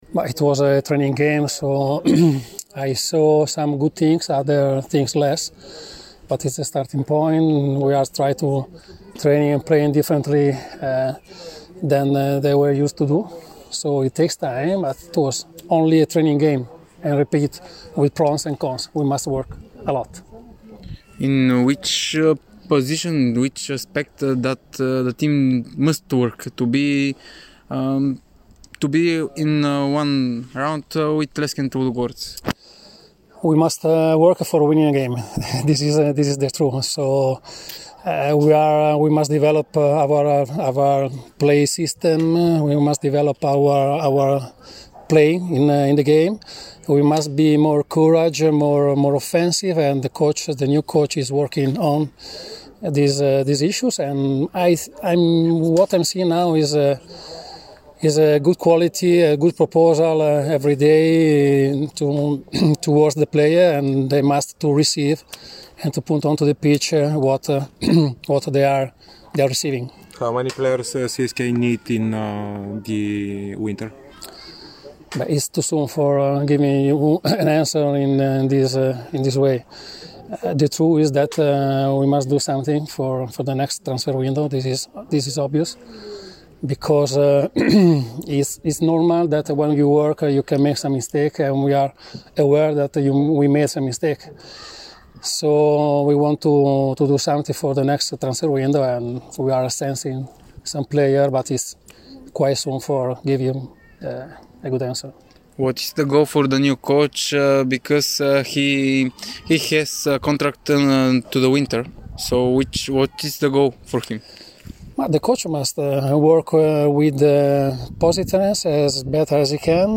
изявление пред медиите